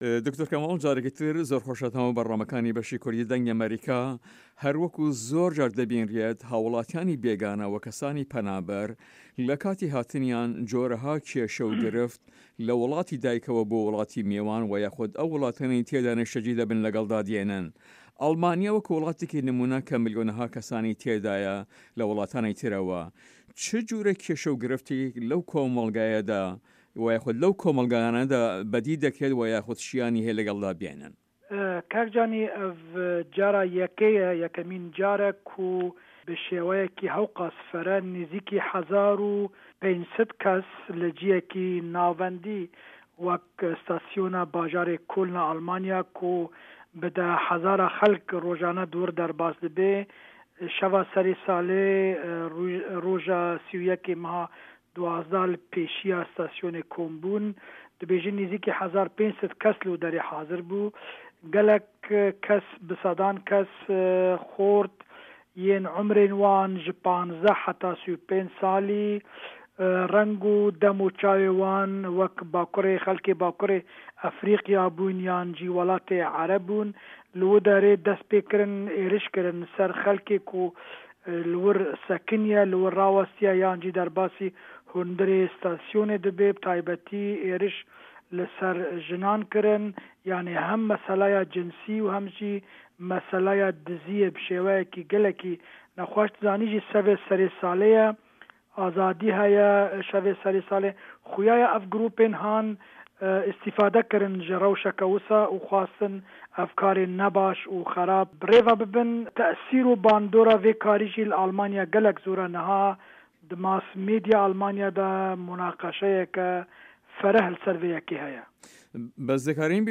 لە هەڤپەیڤینێکدا لەگەڵ بەشی کوردی دەنگی ئەمەریکا دەڵێت" ئەمە یەکمین جارە بە شێوەیەکی زۆر نزیکەی ١٥٠٠ کەس لە شوێنێکی ناوەندا وەکو ئێزگەی شەمەندەفەری کۆلن لە ئەلمانیا کە رۆژانە دەیان هەزار کەس تێدا تێدەپەرێ لە شەوی سەر ساڵ سەدان گەنچ کە تەمەنیان لە نێوان ١٥